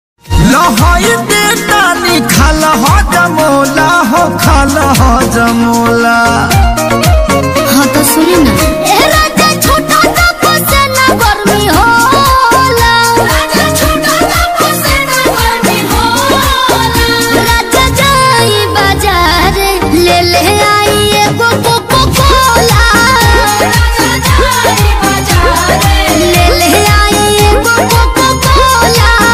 Bhojpuri ringtone 2023